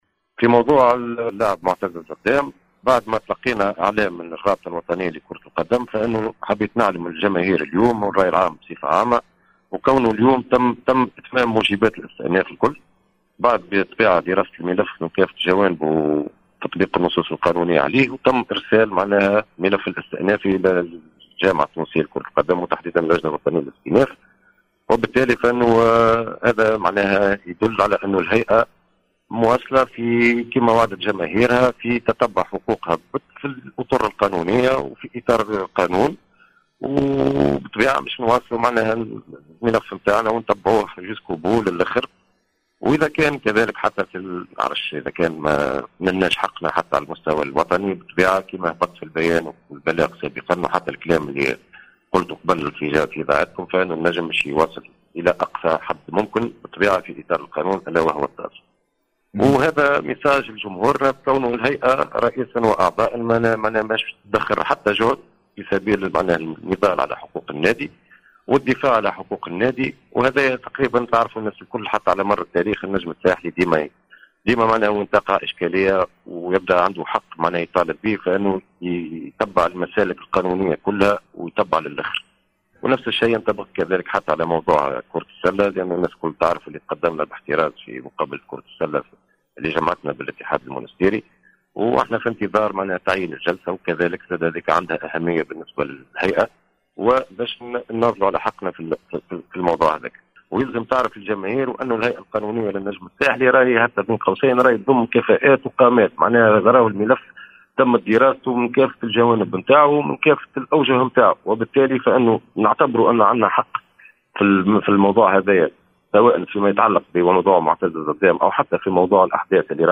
في تصريح خاص به جوهرة أف أم